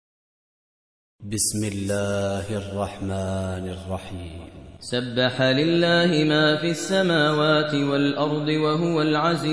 Surah Repeating تكرار السورة Download Surah حمّل السورة Reciting Murattalah Audio for 57.